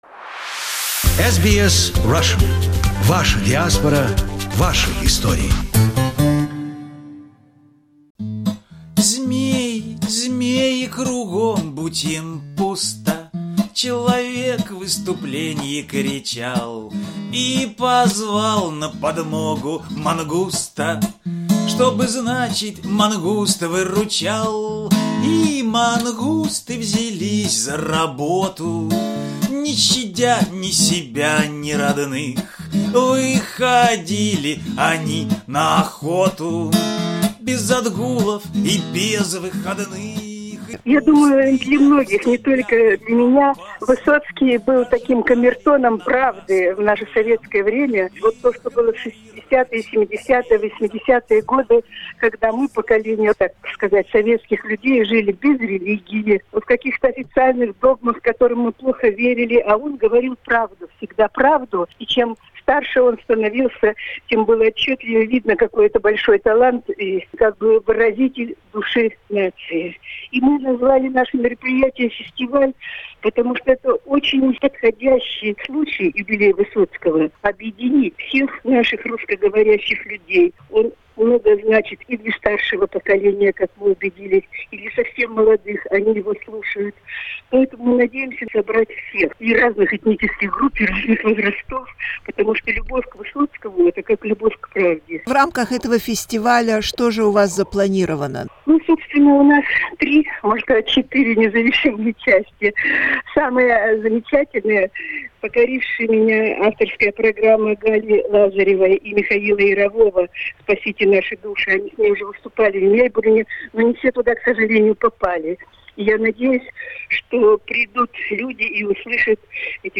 And we asked her about how came the idea for a whole festival not just for a concert?